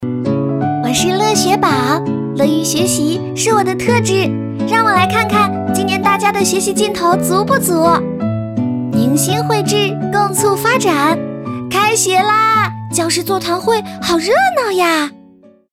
【角色】甜美活泼女童
【角色】甜美活泼女童.mp3